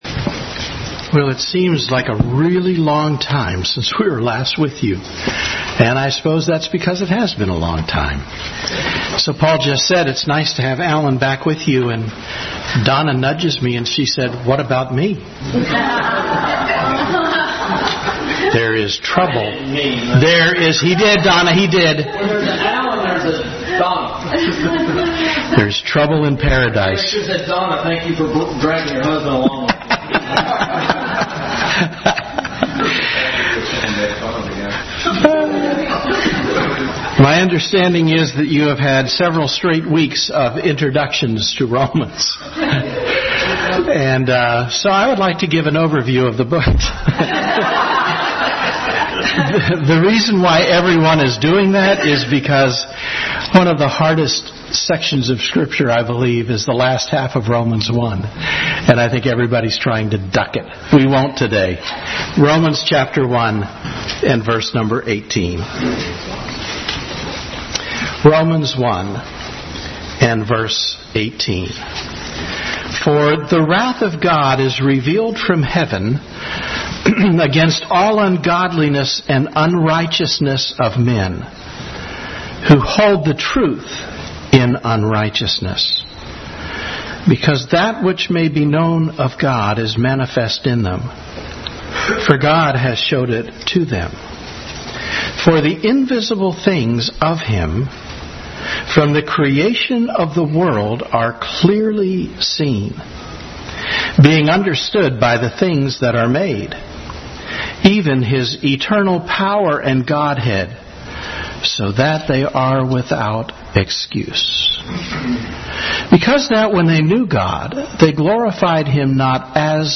Romans 1:18-32 Service Type: Sunday School Bible Text